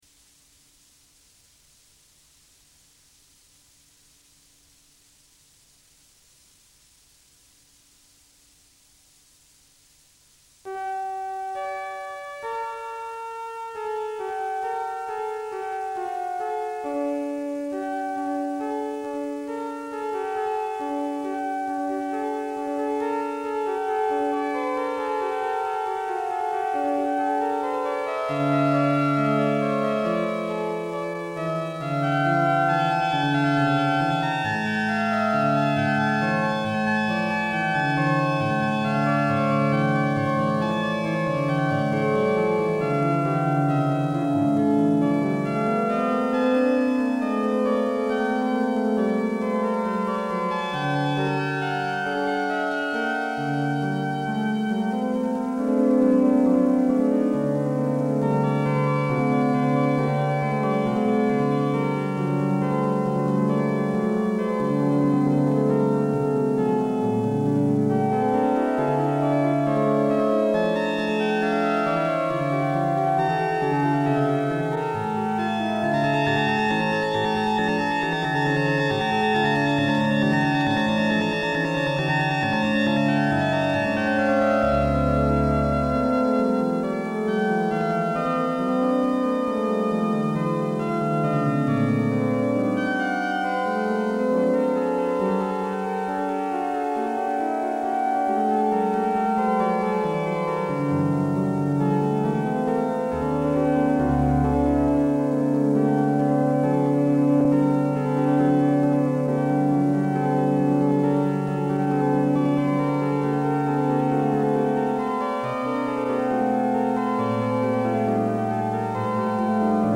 アナログ音源特有の暖かい音がします。
AR(Attack,Release専用エンベロープジェネレータ）でパルス幅が変調できたので、琴のような音も出せました